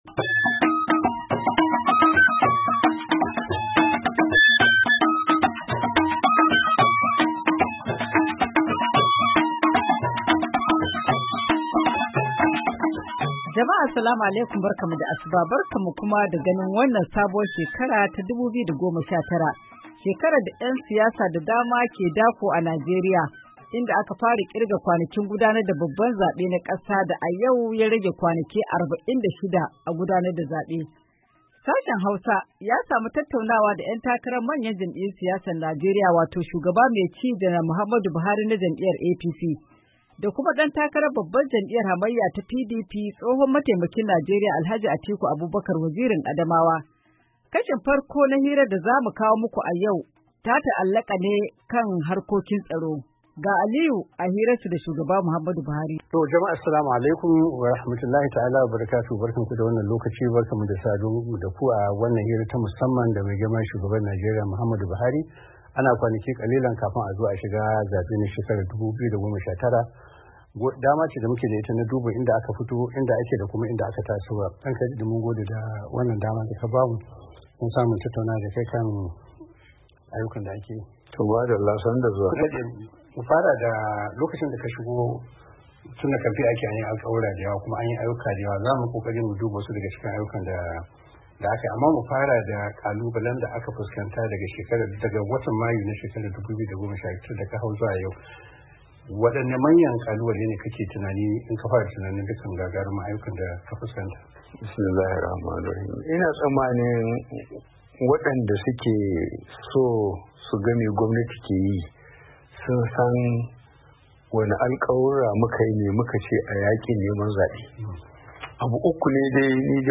Hira Da Shugaba Buhari da Alh Atiku PT1